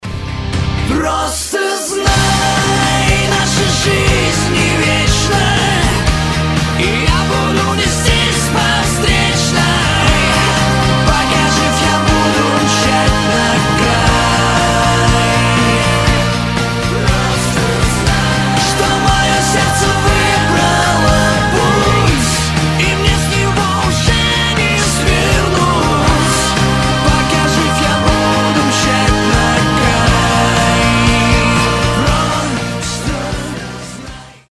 • Качество: 320, Stereo
мужской вокал
громкие
Драйвовые
Cover
Pop Rock
Hard rock
кавер версия